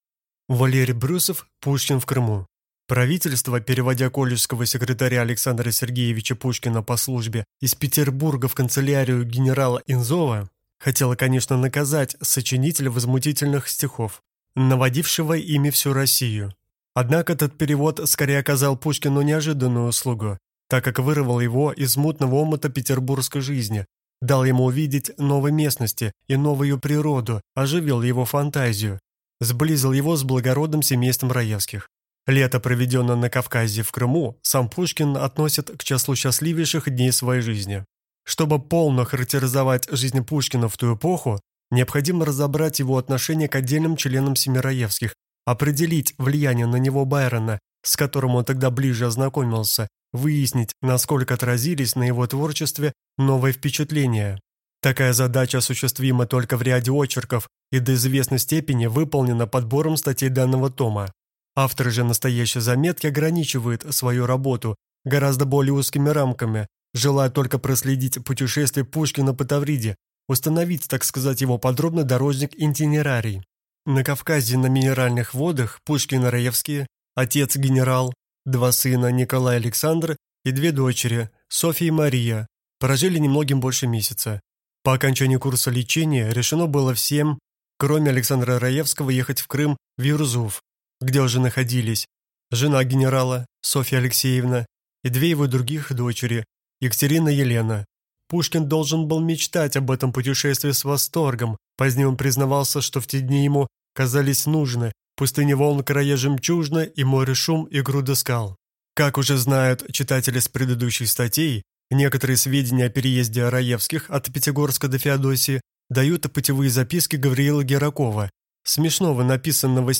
Аудиокнига Пушкин в Крыму | Библиотека аудиокниг